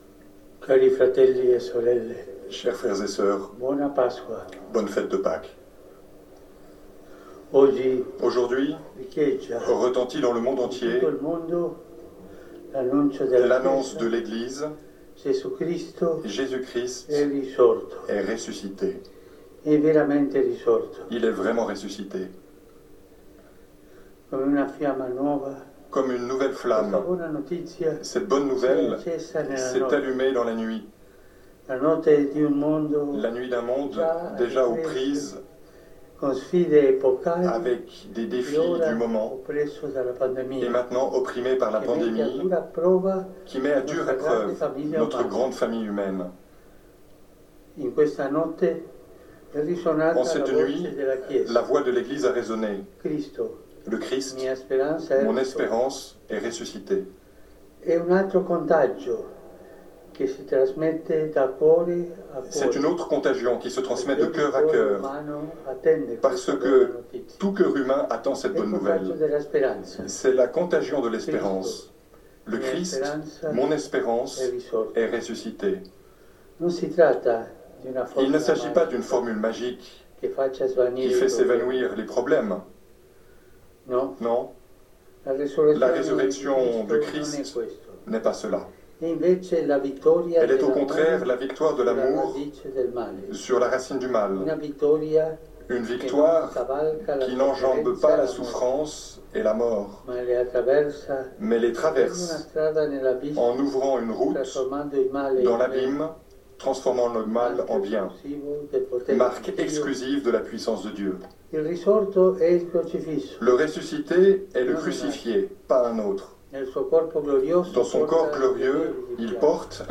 Paques_2020_Urbi_Orbi_Pape_Francois_revu.mp3